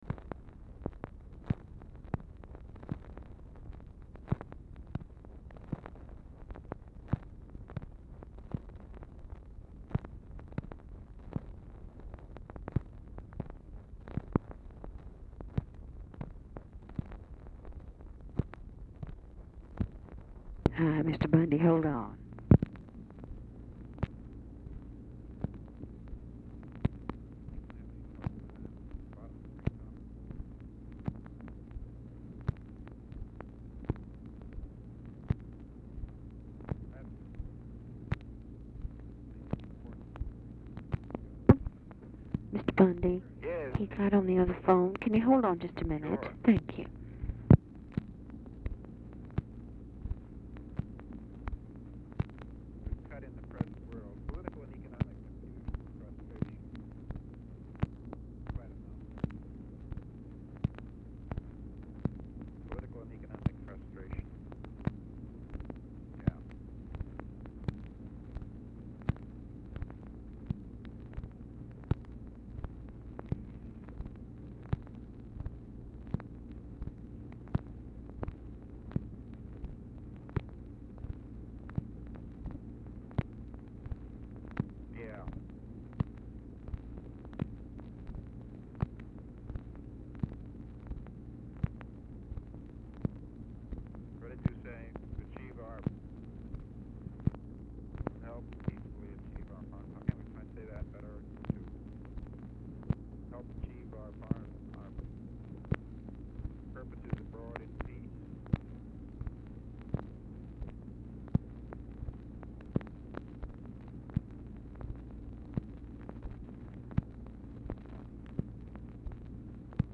Telephone conversation # 1153, sound recording, LBJ and MCGEORGE BUNDY, 1/2/1964, 3:00PM | Discover LBJ
BUNDY ON HOLD FOR 2:00, OFFICE CONVERSATION ON BUNDY'S SIDE WHILE ON HOLD; SKIPPING IN RECORDING, RE-RECORD OF REF #1152
Format Dictation belt
LBJ Ranch, near Stonewall, Texas
OFFICE SECRETARY, OFFICE CONVERSATION